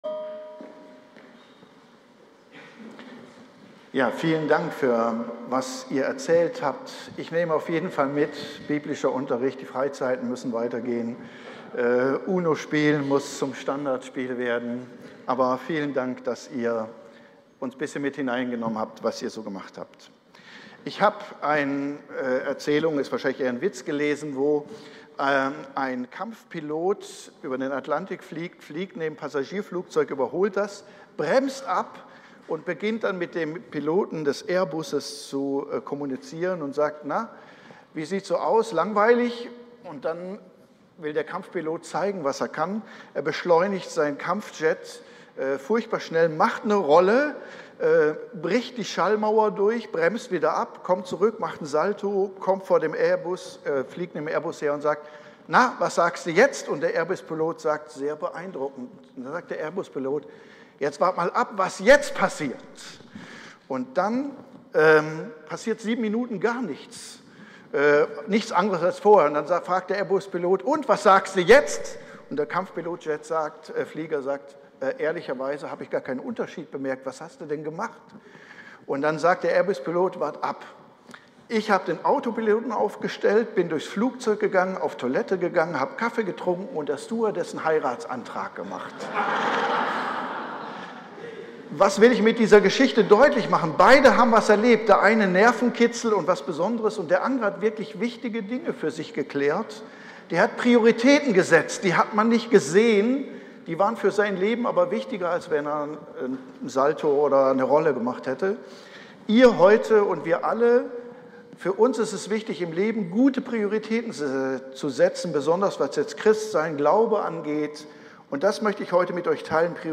Predigt-FeG-Muenster-Segnungsgottesdienst-zum-Abschluss-des-biblischen-Unterrichts-online-audio-converter.com_.mp3